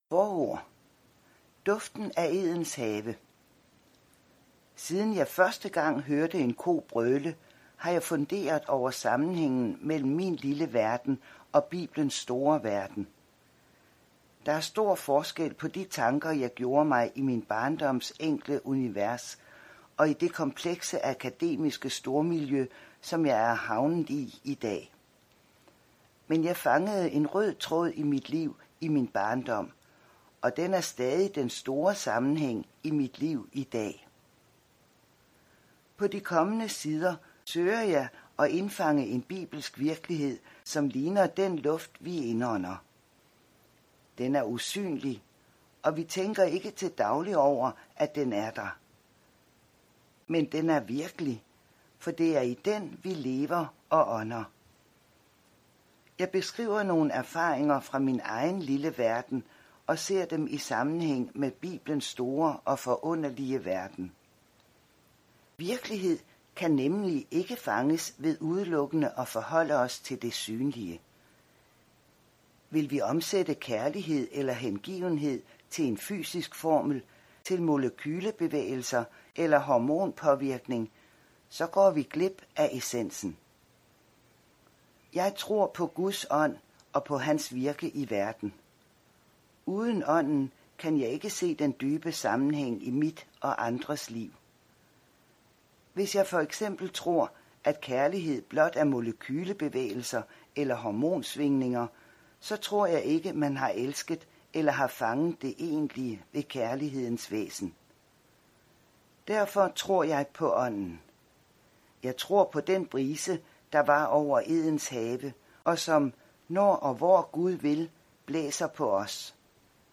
Lydbog